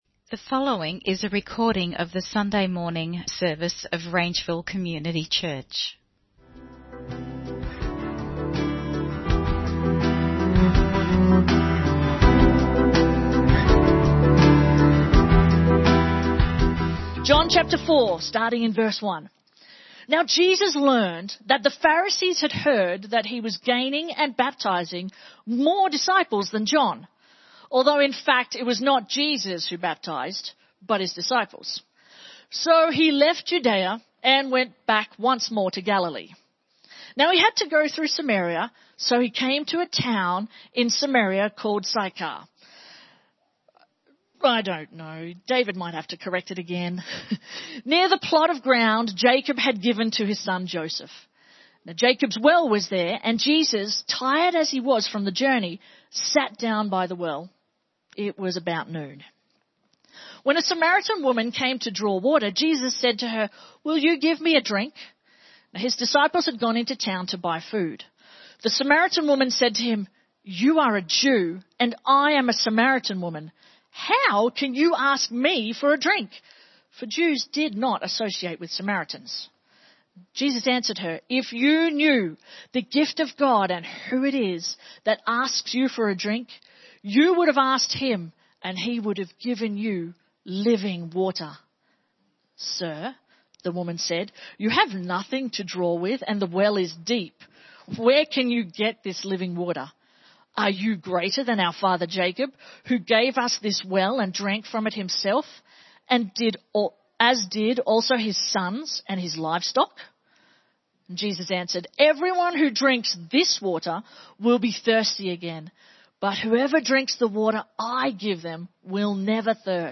The Woman at the Well (Sermon Only - Video + Audio)